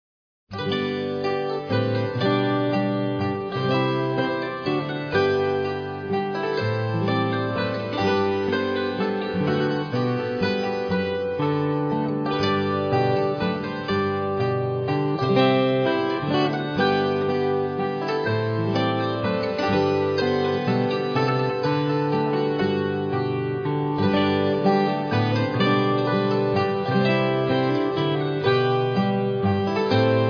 Arranged for dulcimer, recorder, violin, guitar and piano